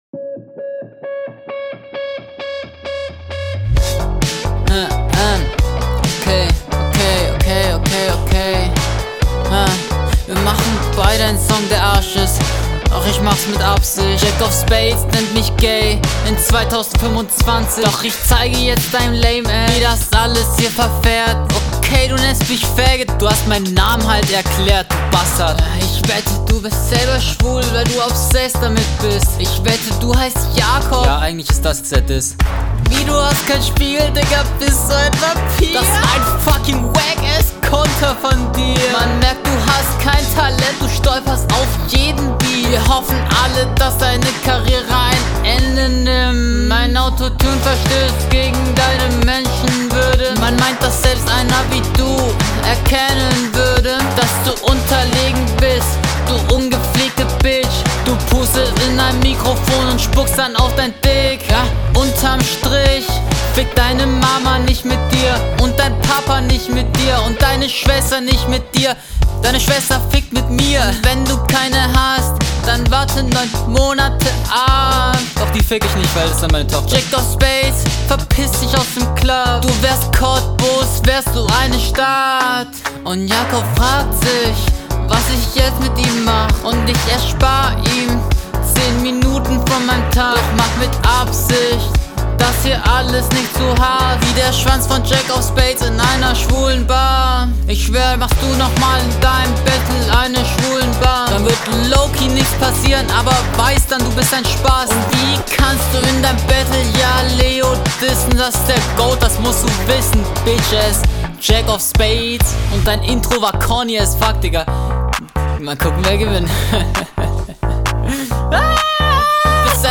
Ich finde er Flow viel besser als der Gegner.